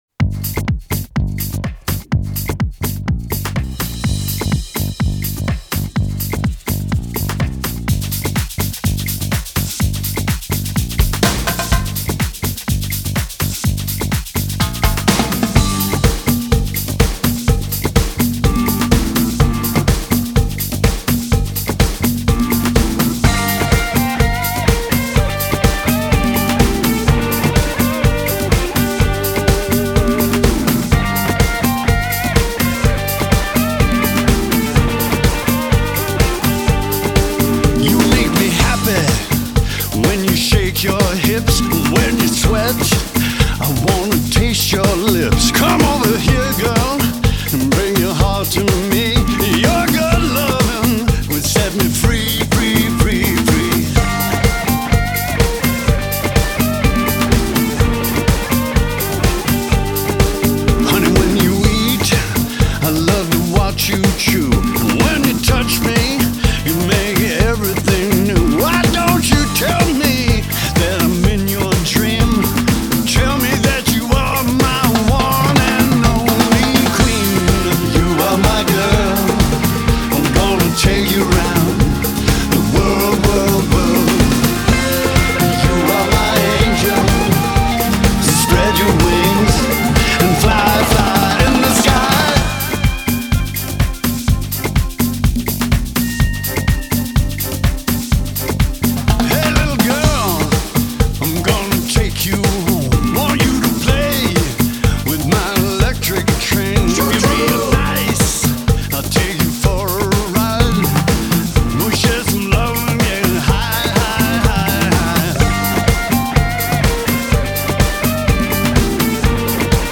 Genre: Classic Rock, Blues Rock